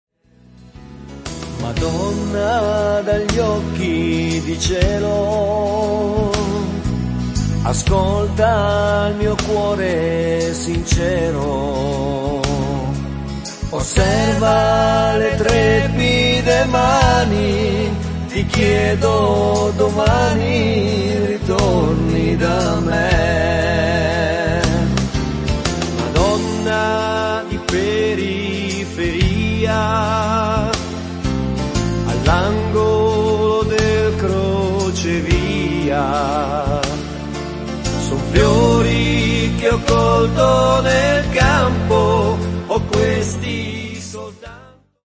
ballata